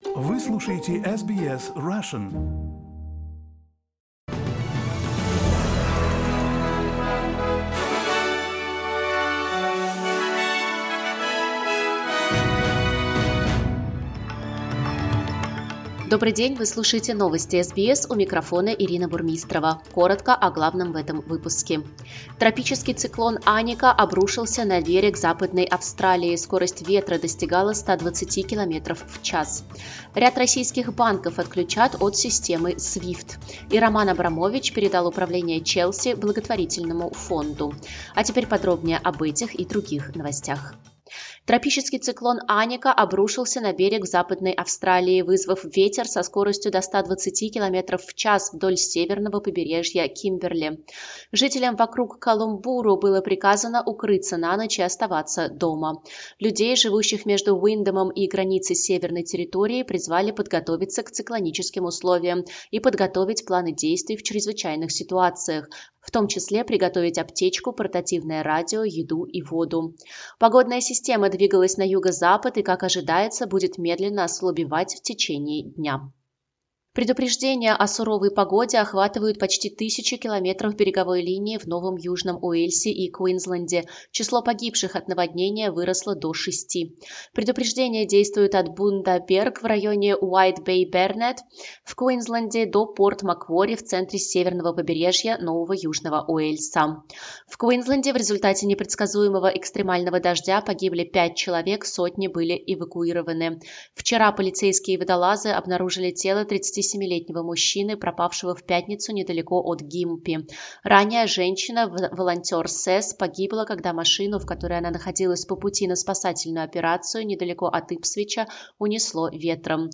SBS news in Russian - 27.02